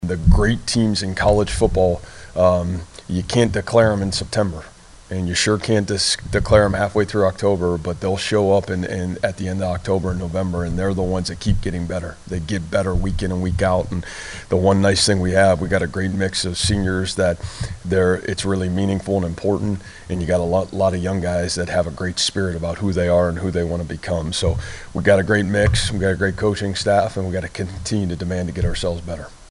That’s Cyclone coach Matt Campbell who was proud of the way his team bounced back from a lopsided loss at Oklahoma.